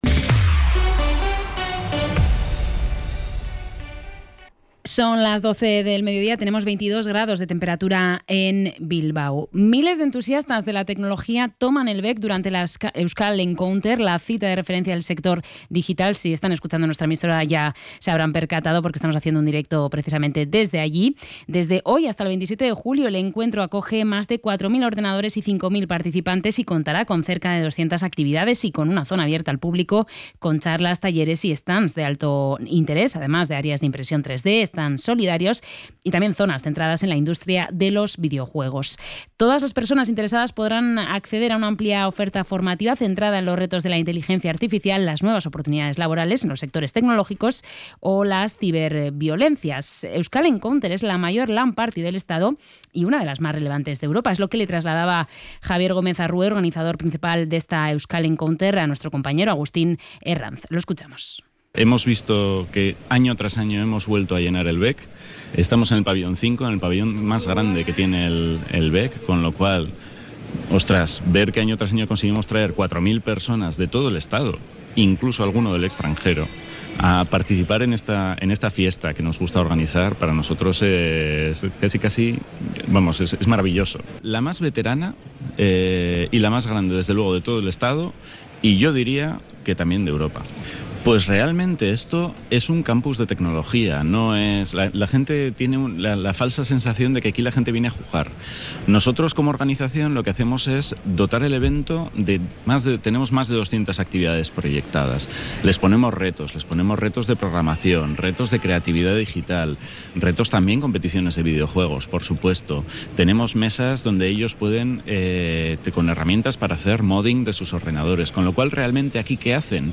Información y actualidad desde las 12 h de la mañana